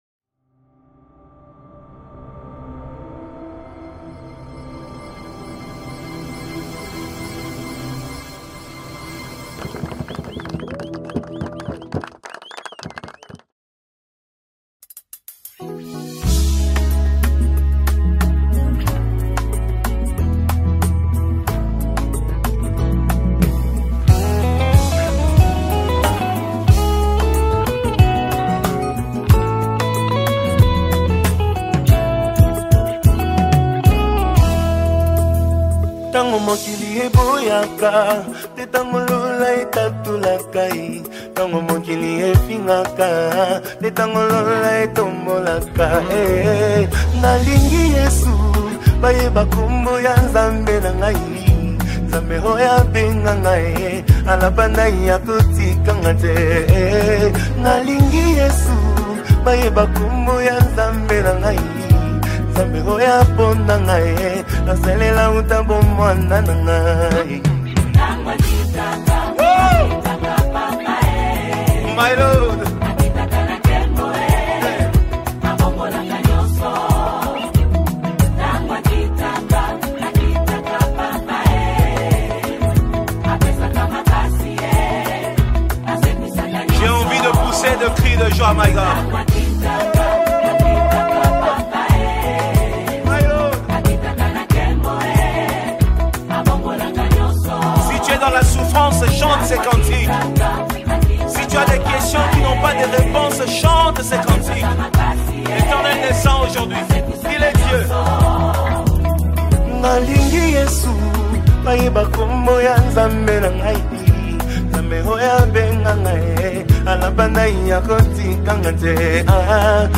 Congo Gospel Music
WORSHIP SONG